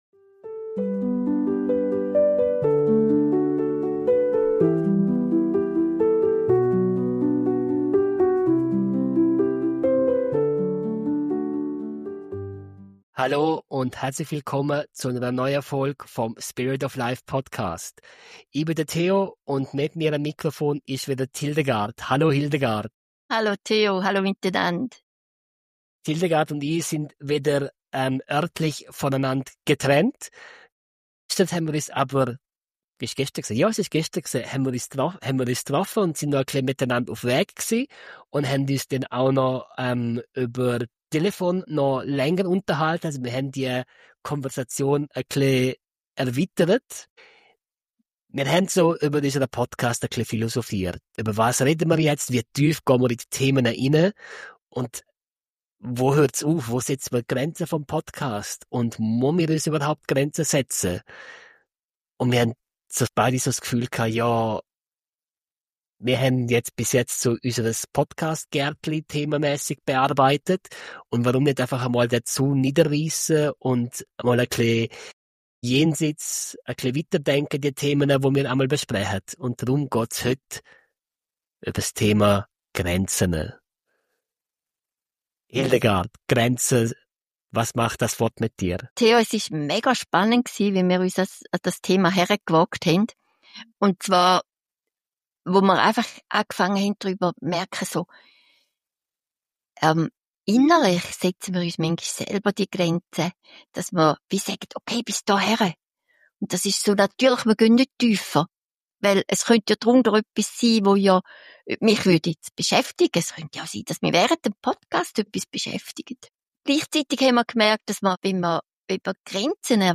Grenzen sind nicht nur Schutz, sondern auch ein Ausdruck von Selbstachtung und Klarheit. Wir beleuchten, warum es so wichtig ist, die eigenen Bedürfnisse wahrzunehmen, wie wir gesunde Grenzen kommunizieren können – und weshalb ein bewusstes „Nein“ oft ein großes „Ja“ zu uns selbst bedeutet. Ein Gespräch über Selbstfürsorge, zwischenmenschliche Nähe und die Balance zwischen Offenheit und Abgrenzung.